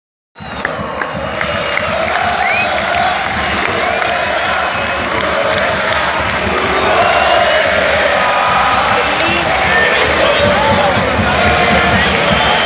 Mestalla clama el ‘Nuno vete ya’ durant el VCF-Betis
Mestalla comença a dir prou davant la inoperància que el conjunt de Nuno Espírito Santo està demostrant als primers compassos de la temporada. Així pues a pocs minuts de que el partit entre el VCF i el Reial Betis arribarà a la seua fi, Mestalla ha entonat un càntic perillós donat les alçades de temporada a la que estem, parlem del “Nuno vete ya”.